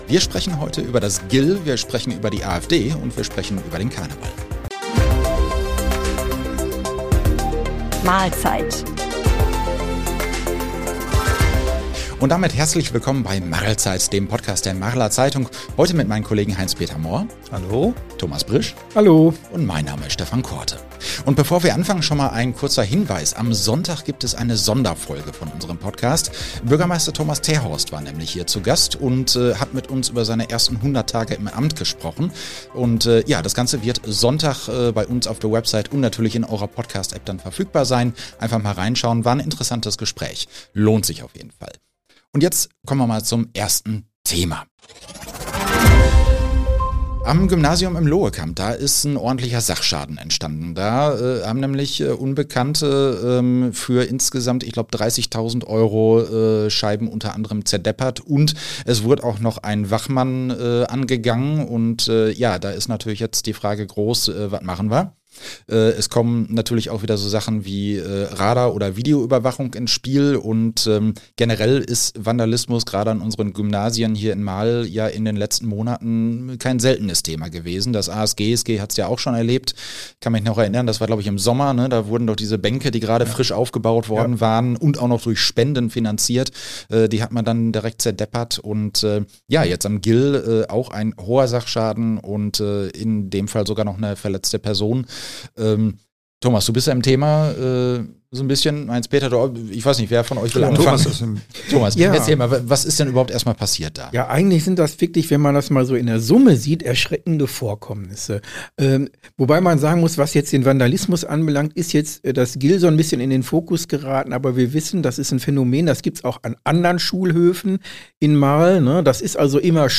Heute geht es um Vandalismus am Gymnasium im Loekamp. Außerdem sprechen wir über den Gerichtsprozess der AfD gegen einen Kellner. Und es steht das lange Karnevals-Wochenende vor der Tür. Im Studio